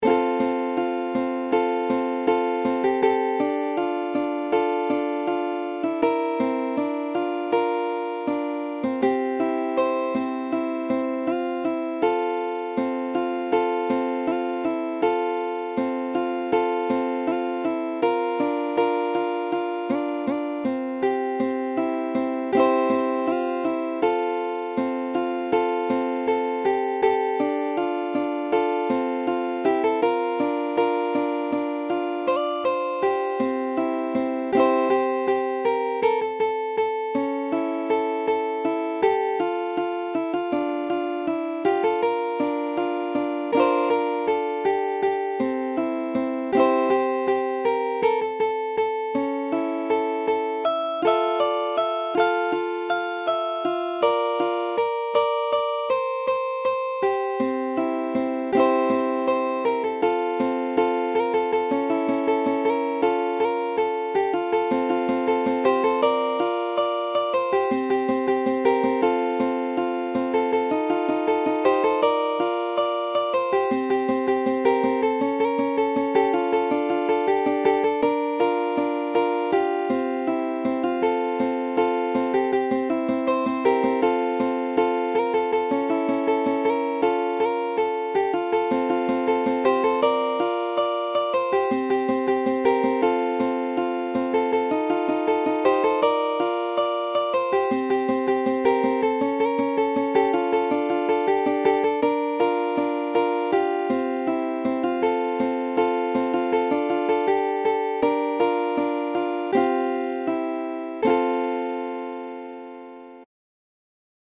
Ukulele Fingerstyle Solo Tab 乌克丽丽 指弹 独奏 谱